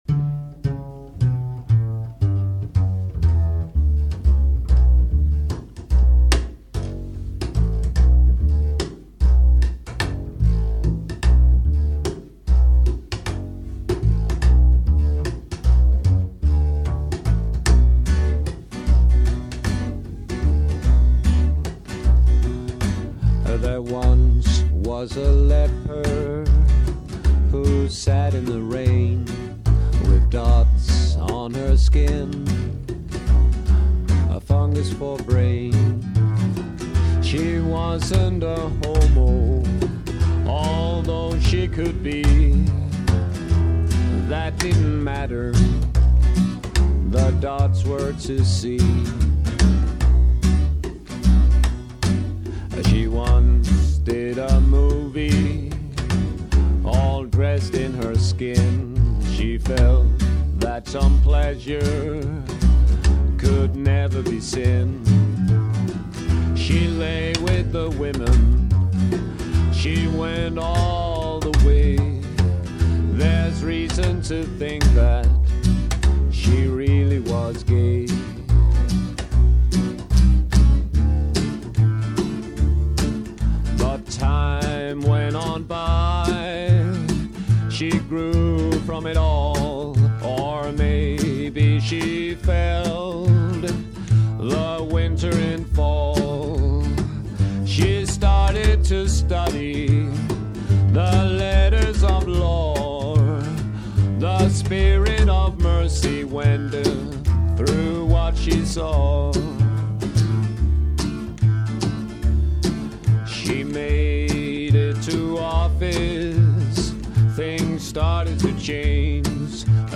Guitar
Bass
Guitar, violin,
vocal, Guitar